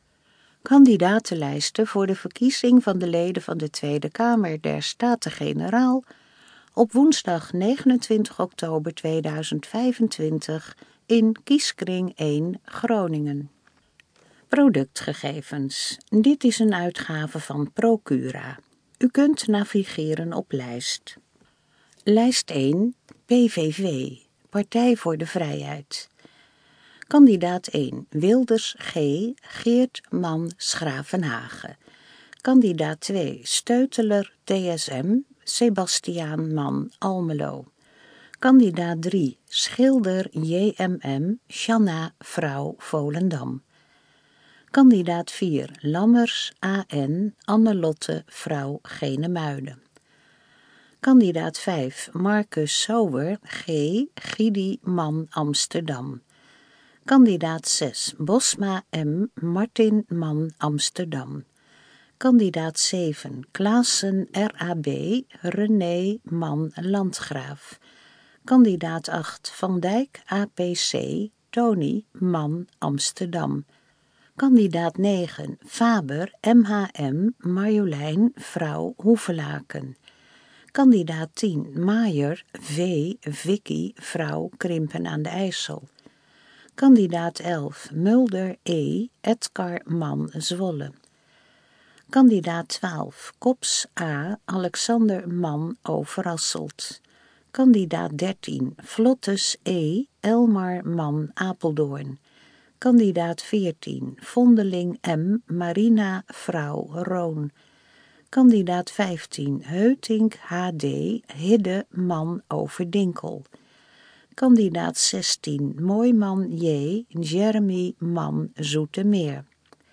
Gesproken_versie_kandidatenlijst_TK2025_Groningen.mp3